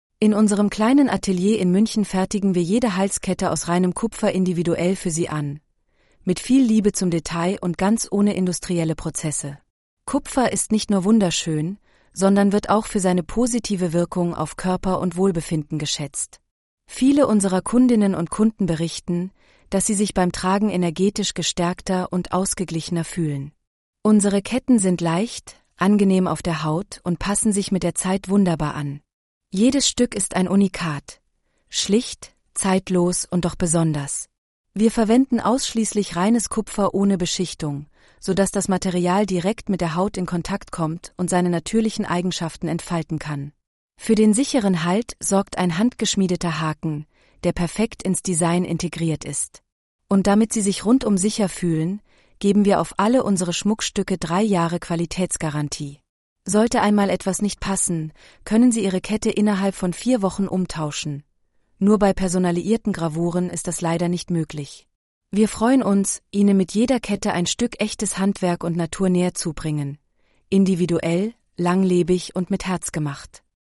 Halskette-ttsreader.mp3